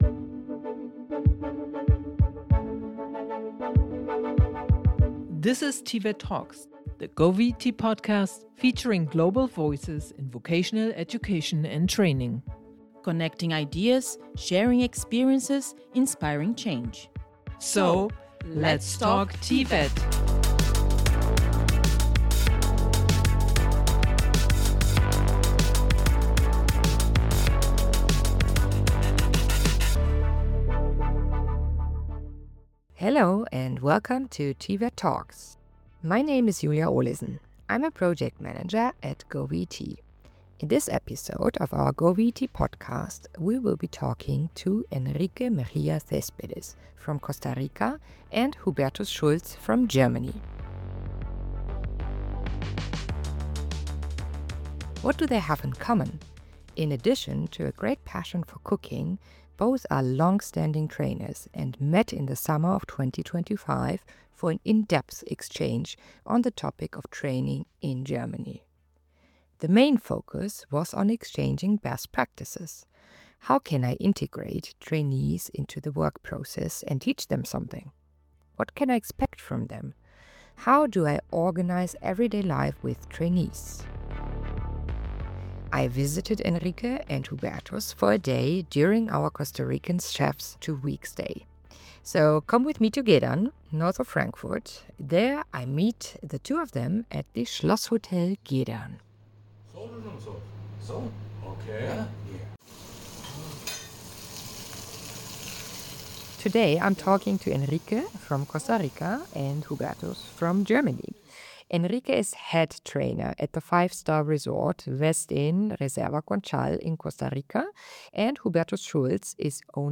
Join us at the Schlosshotel Gedern in Frankfurt to meet them and discuss the differences in dual training, teaching and cooking in their home countries.